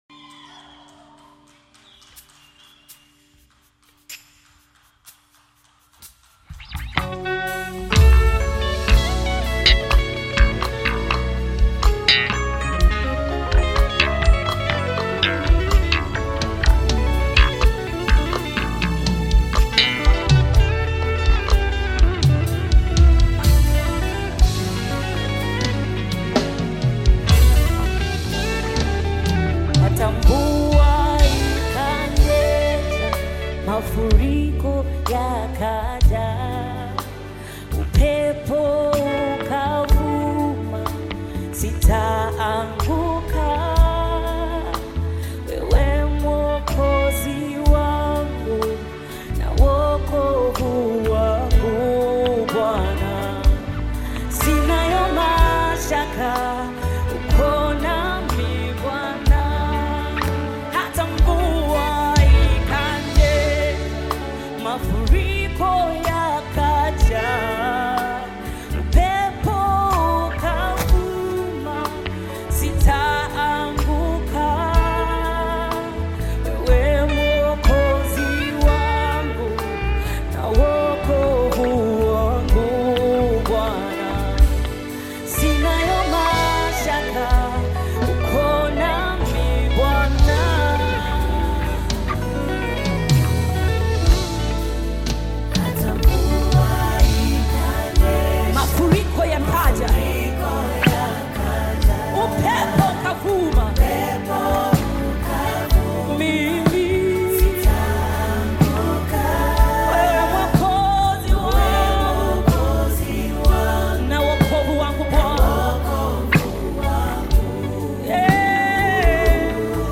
African Music
heartfelt gospel track